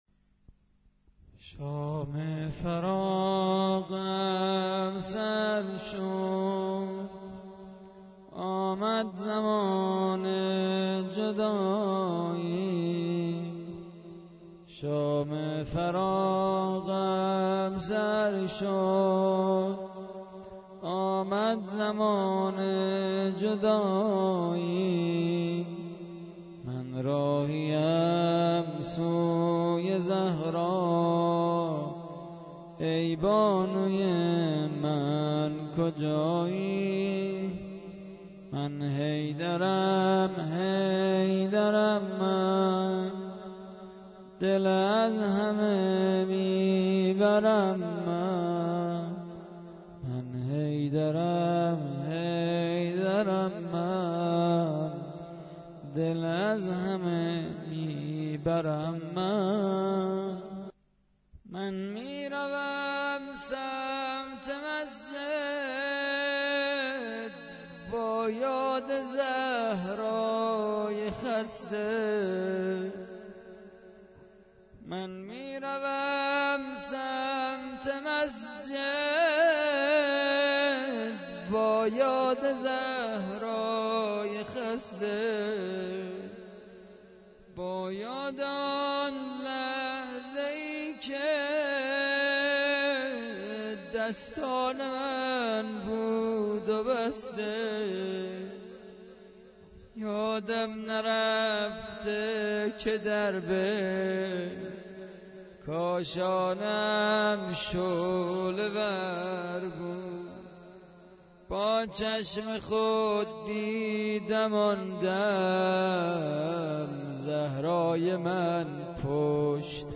متن مداحی شهادت حضرت علی (ع) در شب بیست و یکم ماه رمضان با سبک -( امشب فضای دل من ،‌ در التهاب غم توست )